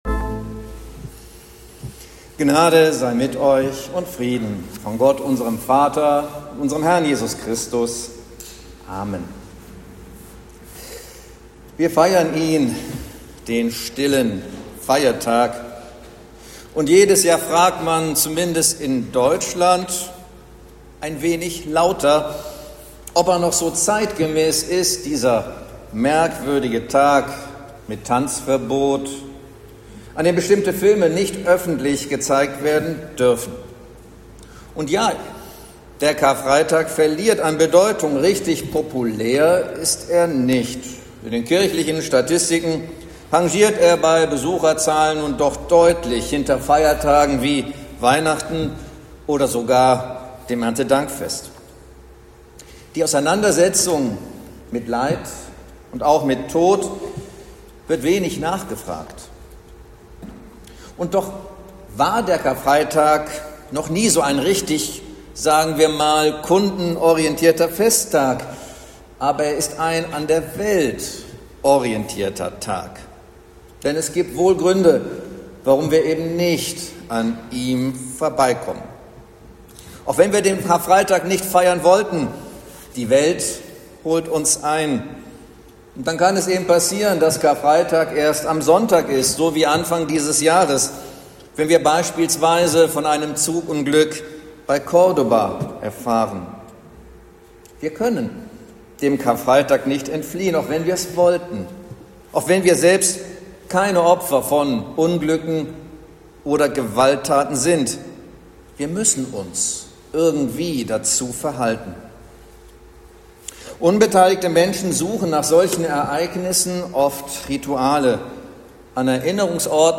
Predigt zum Karfreitag